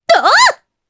Epis-Vox_Attack4_jp.wav